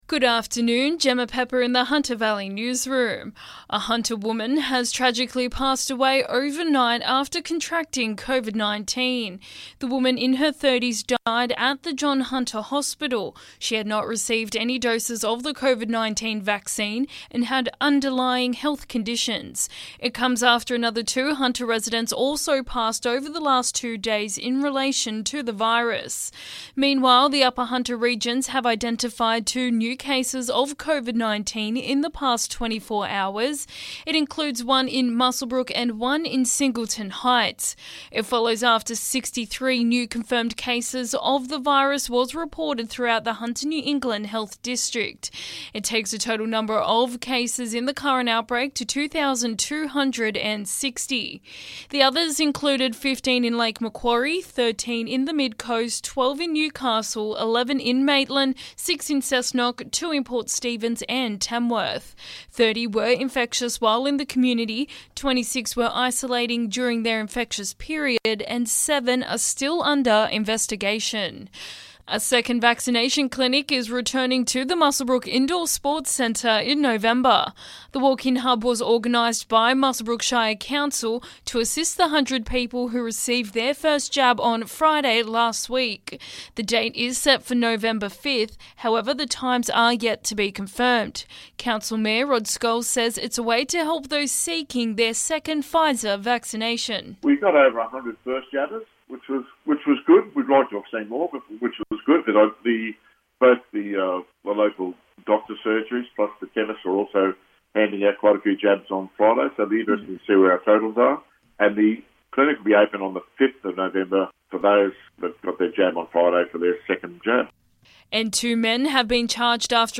LISTEN: Hunter Valley Local Headlines 18/10/21